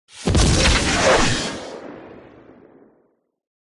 rocket.wav